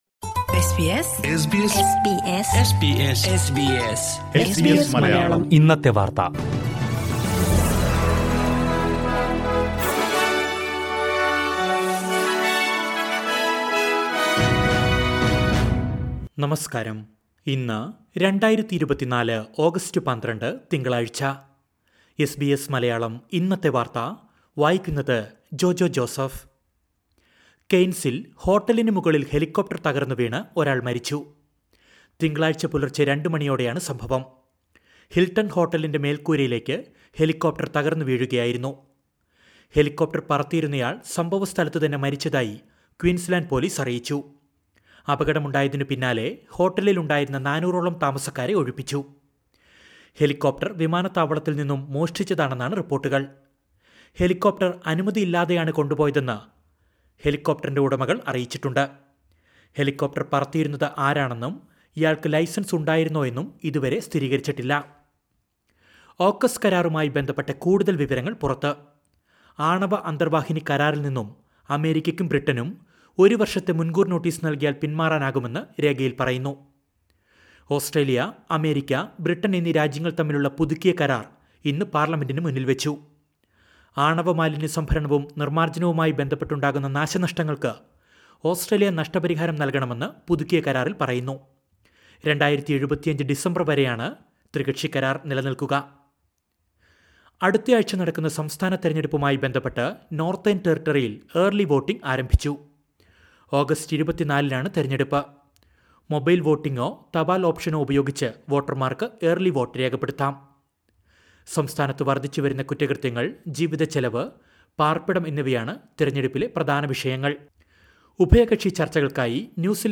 2024 ഓഗസ്റ്റ് 12ലെ ഓസ്‌ട്രേലിയയിലെ ഏറ്റവും പ്രധാന വാര്‍ത്തകള്‍ കേള്‍ക്കാം...